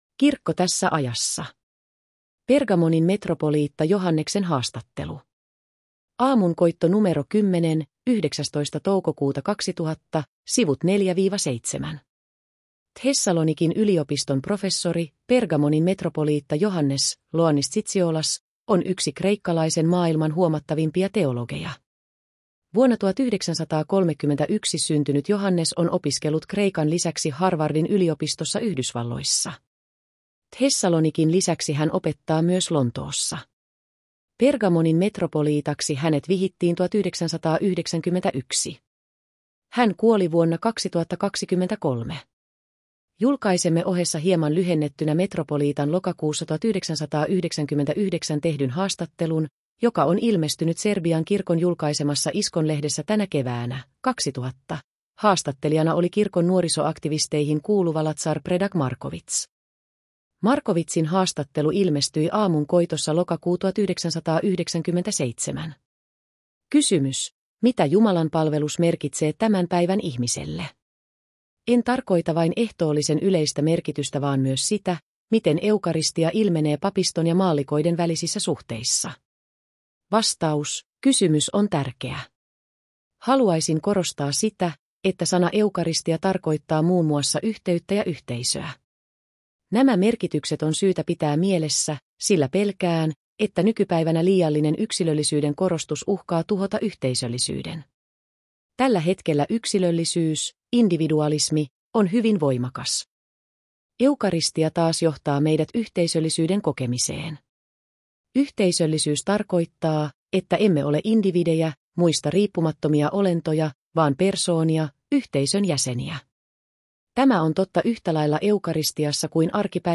Mp. Johannes Zizioulasin haastattelu osa 1